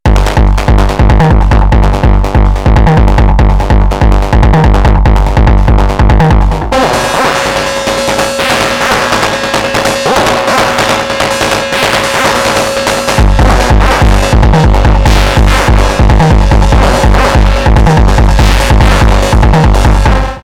Here are two things with just the analog Tracks on the Syntakt: